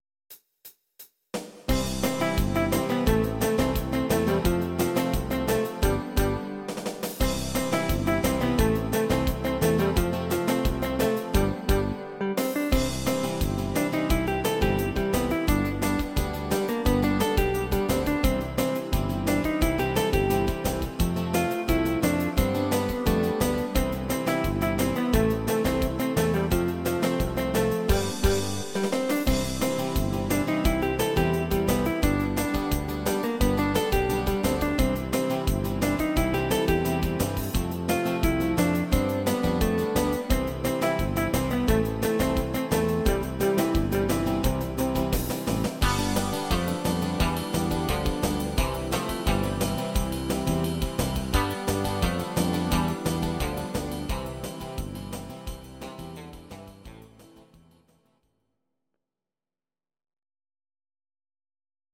These are MP3 versions of our MIDI file catalogue.
Please note: no vocals and no karaoke included.
instr. Gitarre